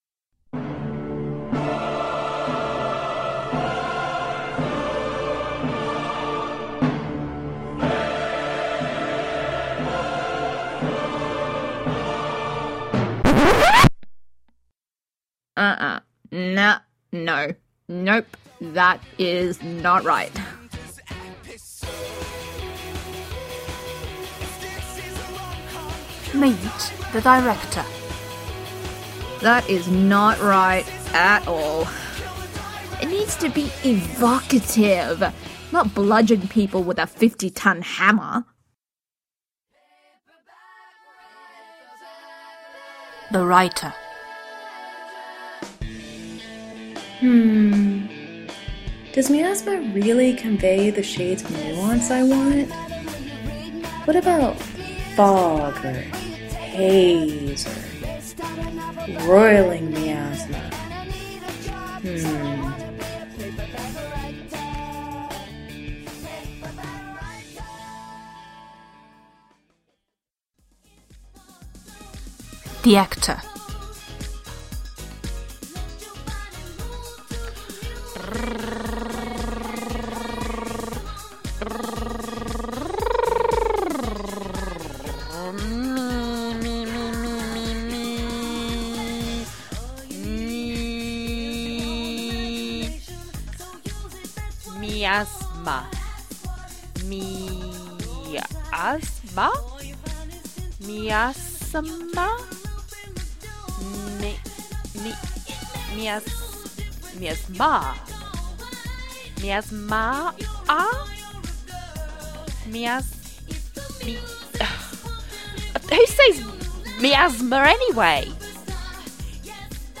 Trailer for Pod Together Lightning, Round 6.
CAST (in order of appearance):